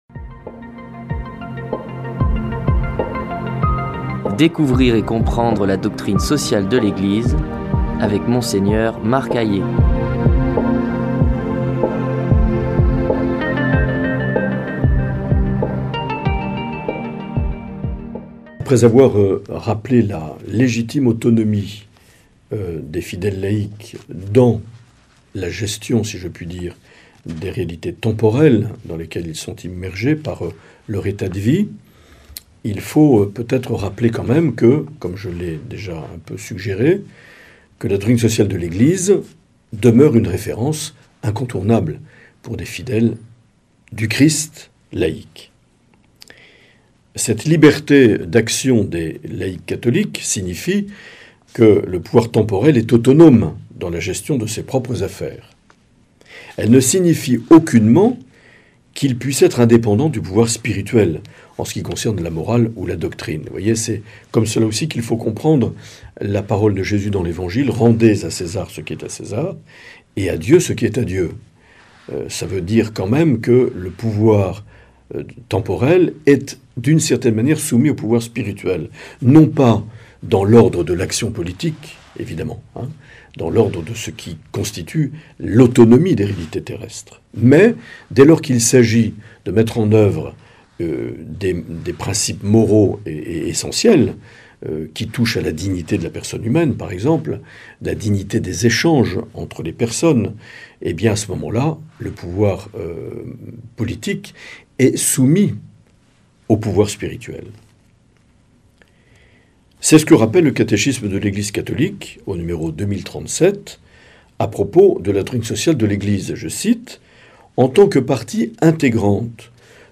Présentateur(trice)